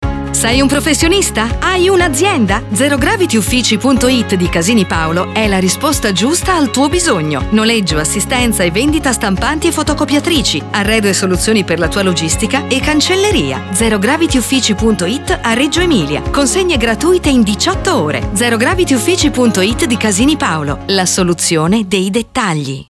ASCOLTA IL NOSTRO SPOT in onda su Radio Bruno!
spotradio.wav